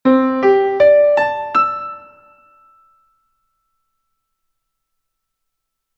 An example of a wider interval is the perfect fifth, with an just interval of 3:2 and a common ratio of 1.5. Let's take a look at the first five notes of the scale (C - G - D - A - E):
Beautiful and melodious!
Notice as the interval widens, the intervals become more happy, spaced out and fewer within an octave; there is more room to breathe.
perfect-fifth-scale.mp3